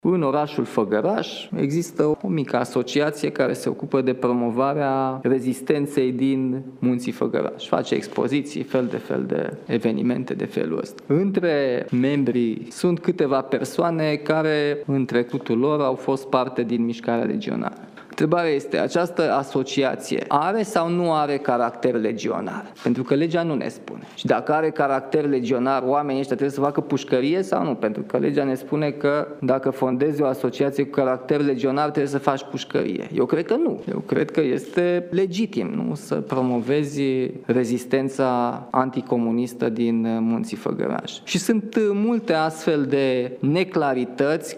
Într-o conferință de presă ținută la începutul acestei săptămâni, președintele a atras atenția că legea nu arată clar ce se întâmplă cu unele asociații ai căror membri au fost, în trecut, parte din mișcarea legionară.